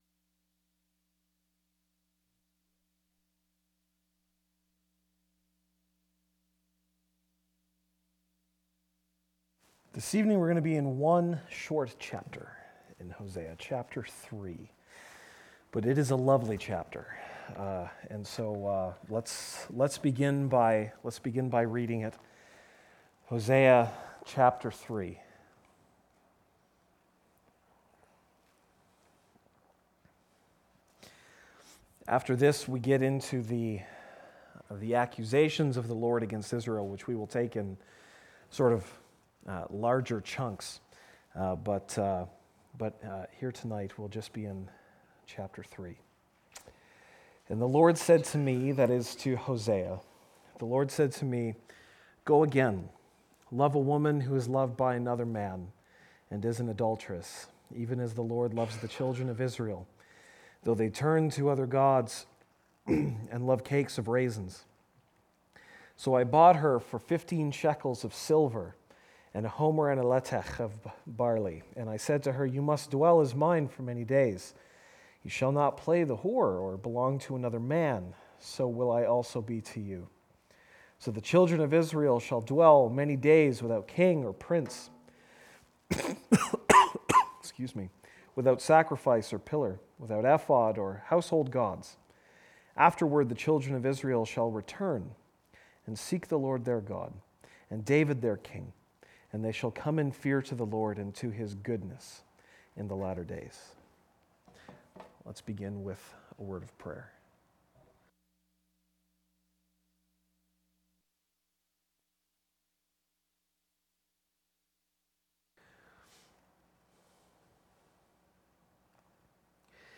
January 17, 2016 (Sunday Evening)